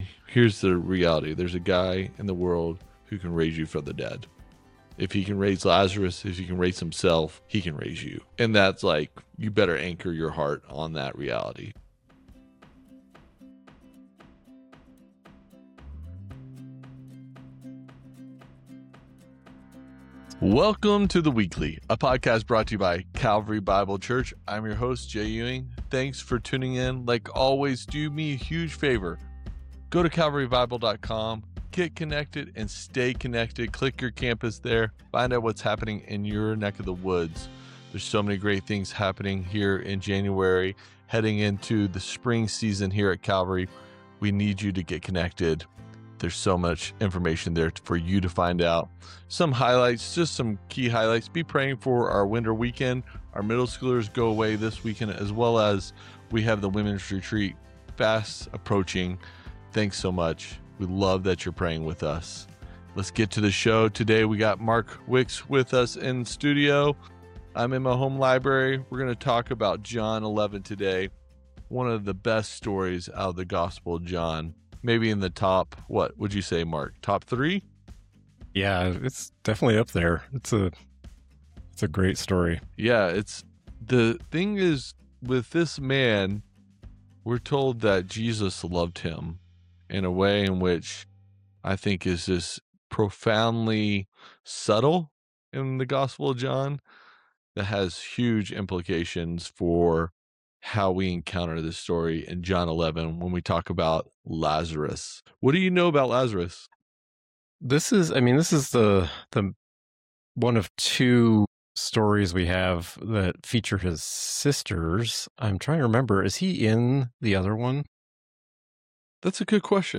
a thoughtful conversation through John 11, the story of Lazarus.